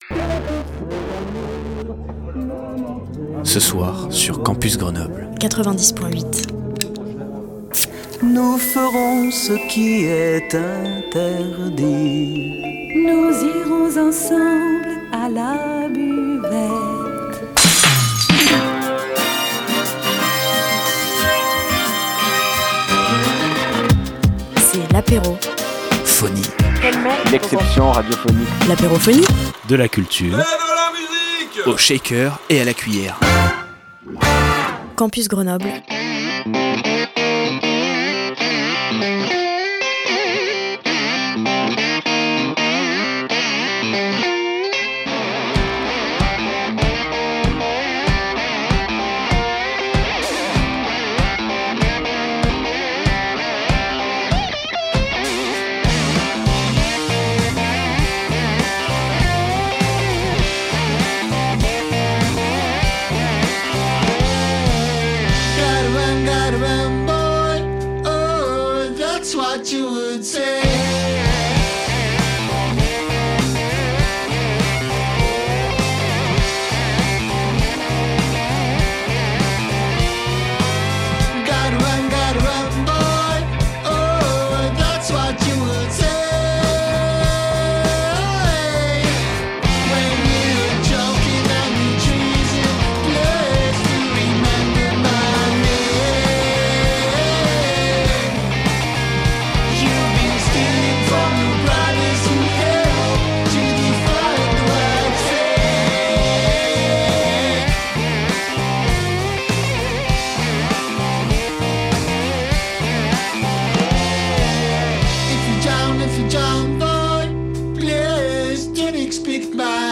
En pleine tournée actuellement, le groupe sortira un nouvel album en janvier 2026, et d’ici leur retour vers nos régions, n’hésitez pas à écouter ce petit entretien ponctué de plusieurs de leurs morceaux, afin de (re)découvrir cette belle formation.